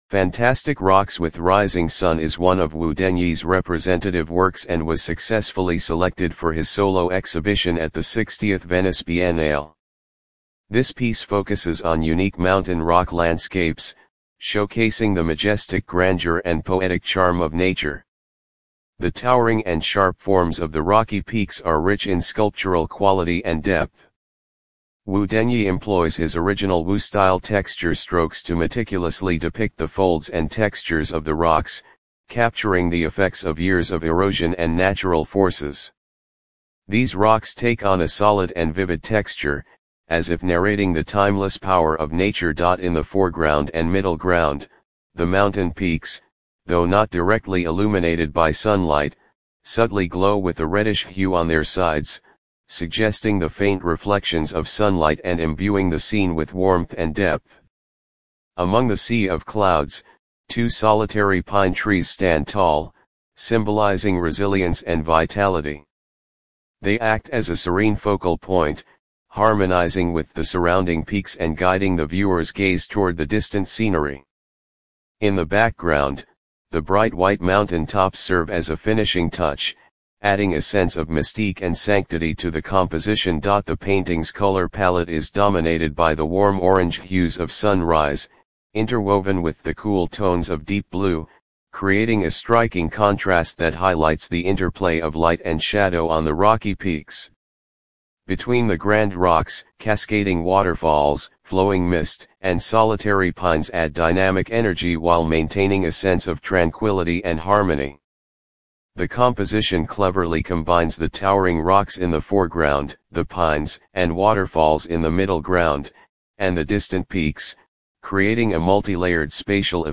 英文語音導覽